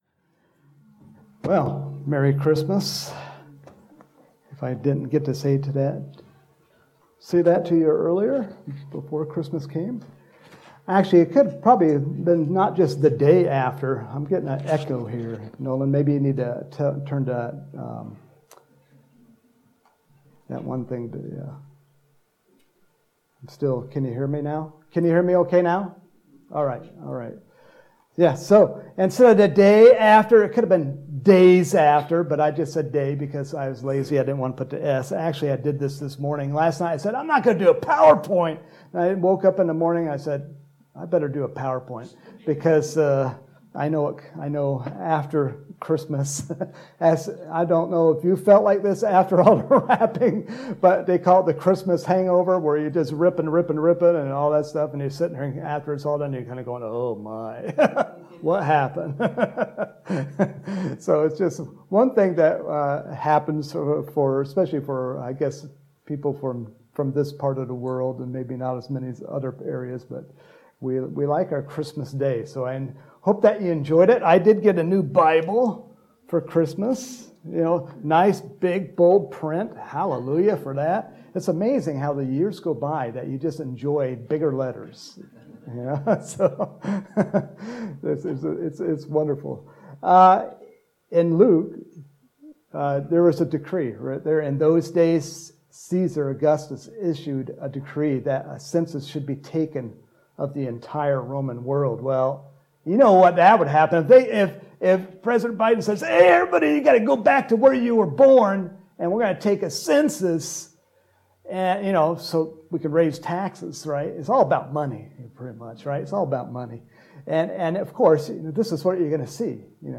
Home › Sermons › December 26, 2021
Sunday Morning Sermon